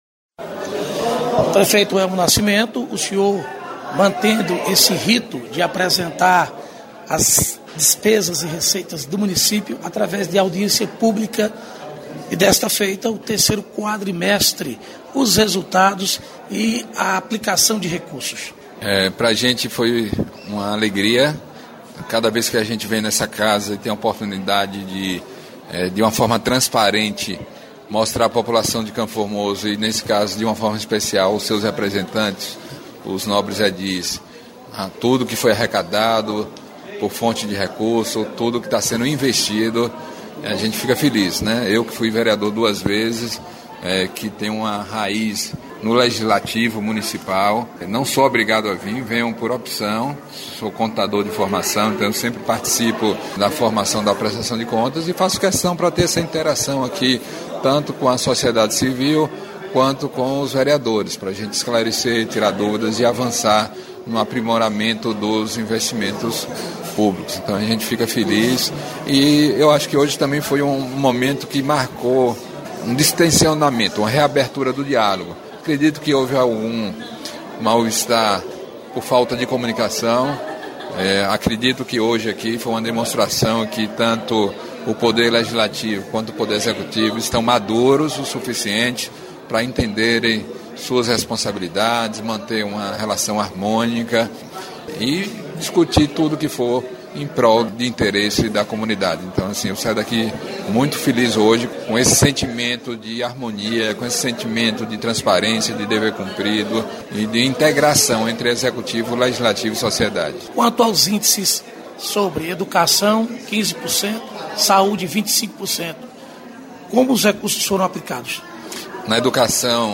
Prefeito Elmo Nascimento em audiência pública para prestação de contas das finanças do município